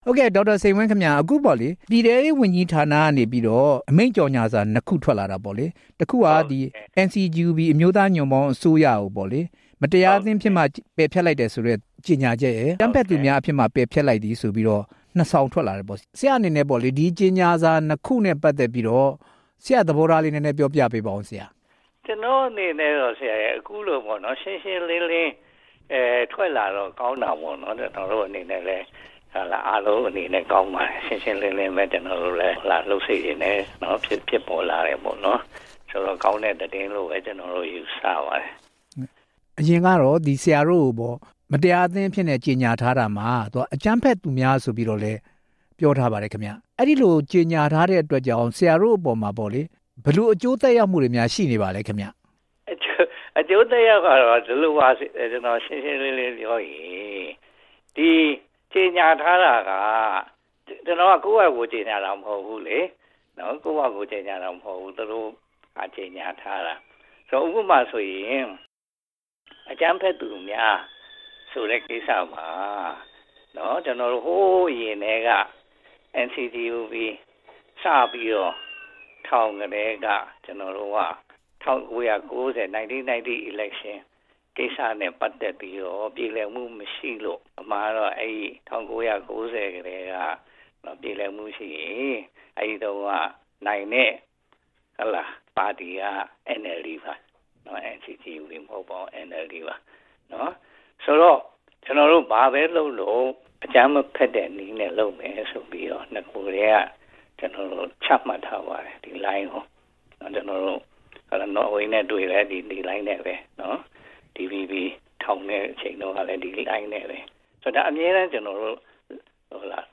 မတရားအသင်းပယ်ဖျက်တဲ့အကြောင်း NCGUB ဝန်ကြီးချုပ်ဟောင်း နဲ့ မေးမြန်းချက်